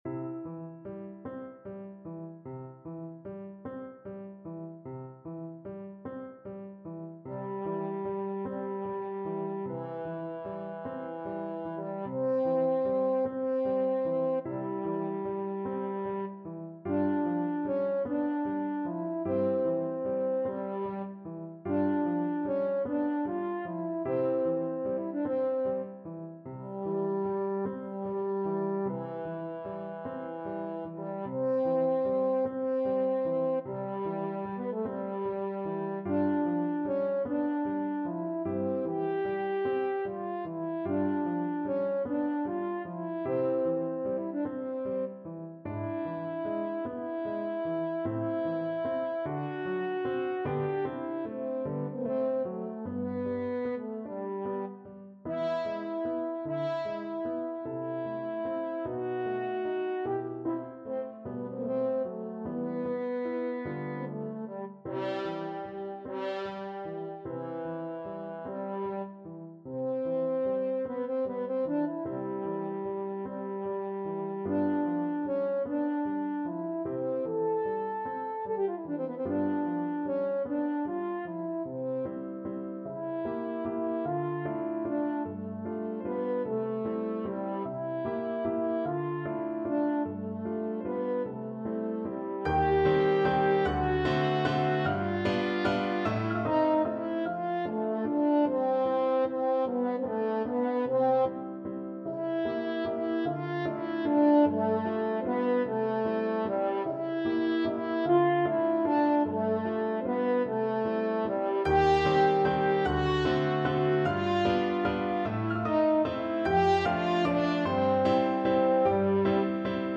Largo
Classical (View more Classical French Horn Music)